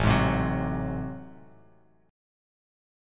Listen to AmM13 strummed